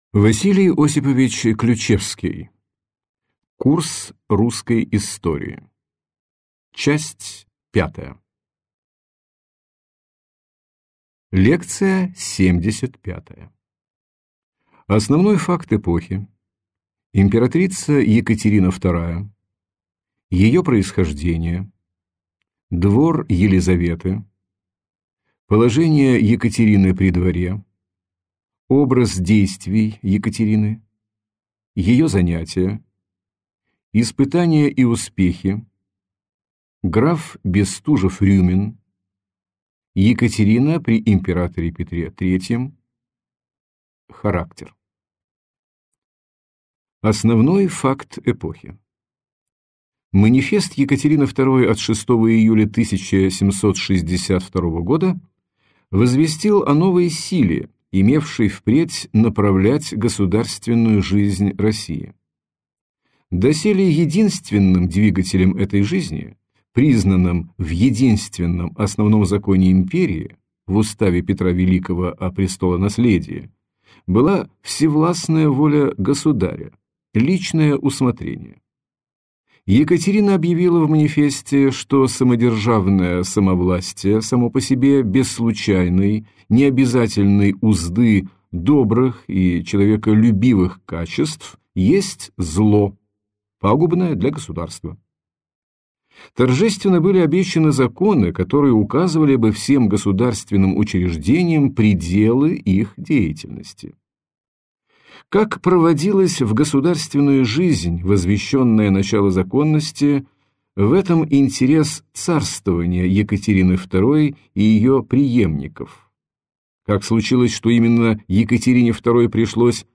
Аудиокнига Русская история. Часть 5 | Библиотека аудиокниг